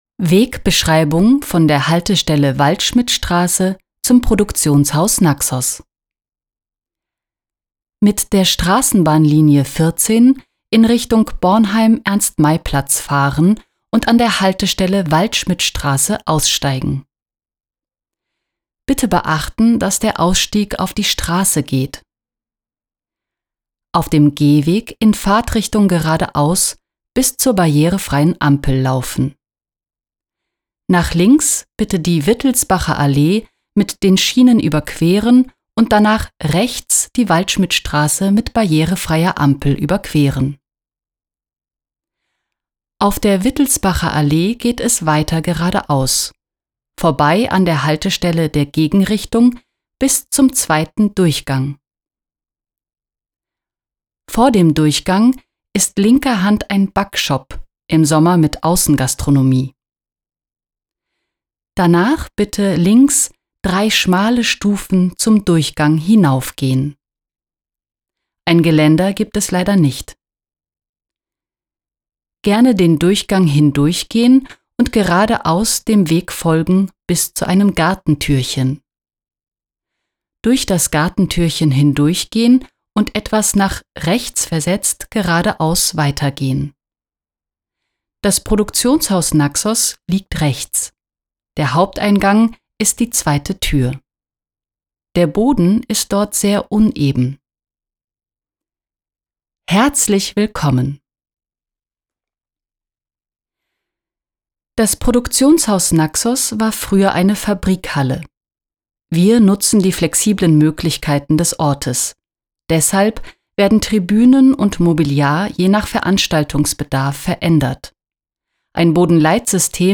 Wegbeschreibungen, insbesondere für blinde und sehbehinderte Personen
naxos-wegbeschreibung-waldschmidtstr.mp3